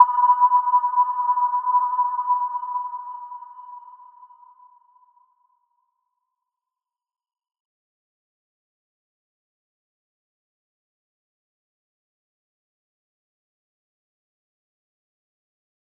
Little-Pluck-B5-mf.wav